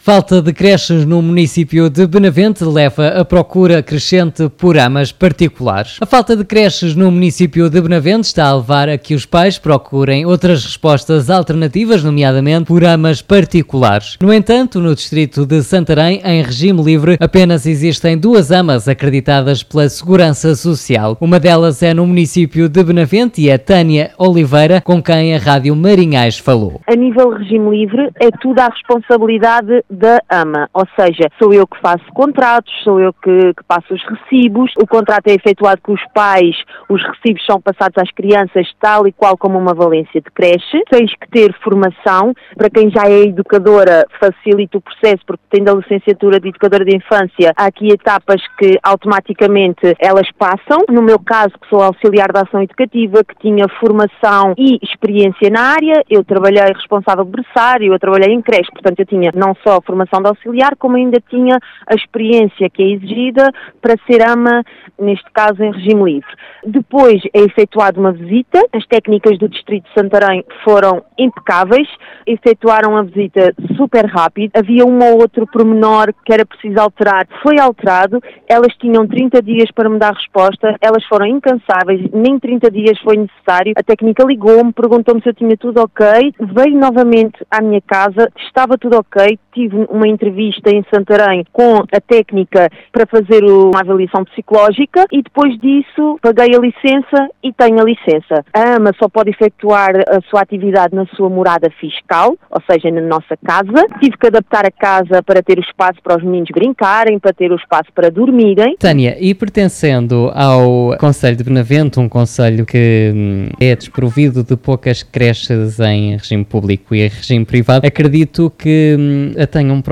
Entrevistada pela Rádio Marinhais